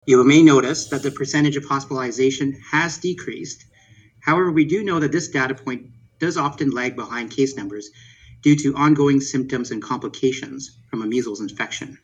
Southwestern Public Health held a media briefing Thursday afternoon to update the community on the measles outbreak across the region.